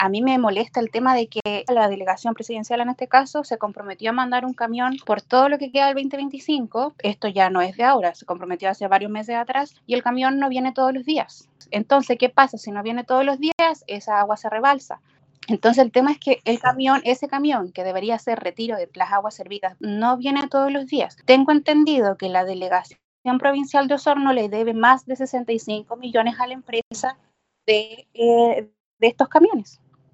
vecina-pichil-2.mp3